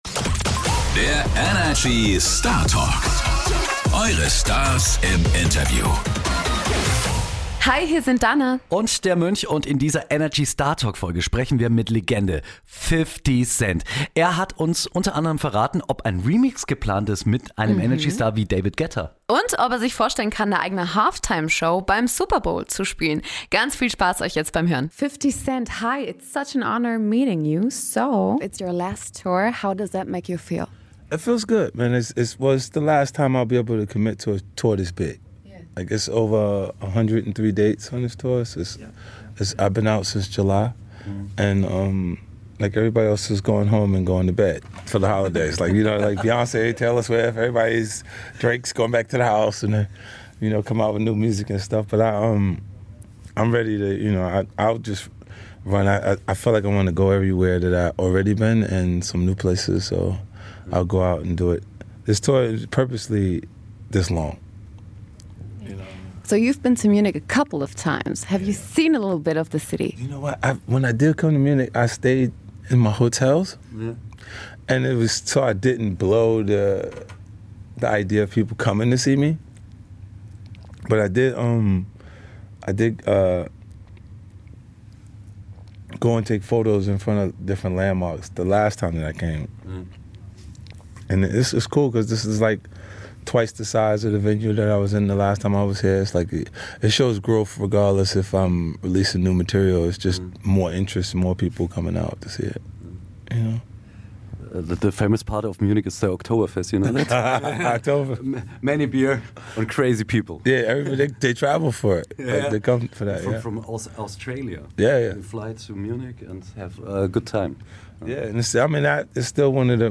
Wir haben die Hip Hop Legende getroffen und erfahren, ob er sich vorstellen könnte, beim Superbowl aufzutreten. Außerdem sprechen wir mit ihm über seine letzte anstehende Tour, seine Karriere als Schauspieler und zum Ende hat er sogar noch eine Lebensweisheit für uns parat.